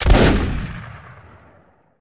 musket_shot.ogg